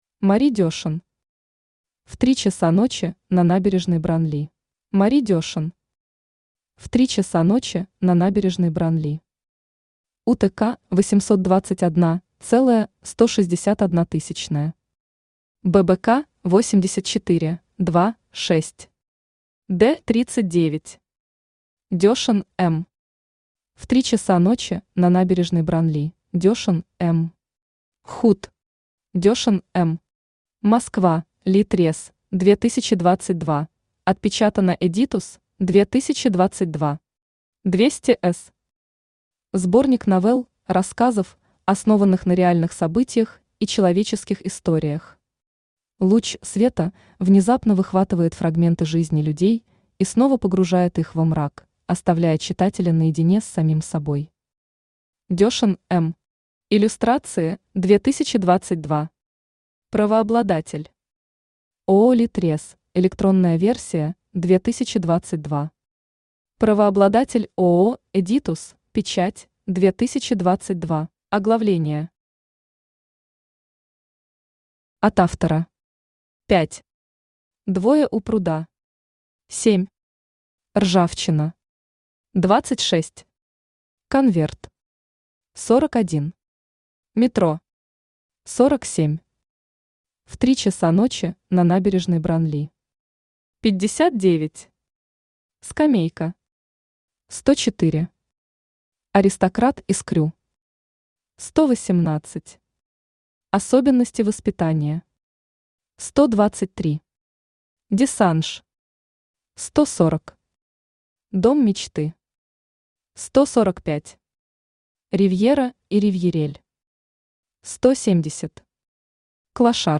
Аудиокнига В три часа ночи на набережной Бранли | Библиотека аудиокниг
Aудиокнига В три часа ночи на набережной Бранли Автор Мари Дешен Читает аудиокнигу Авточтец ЛитРес.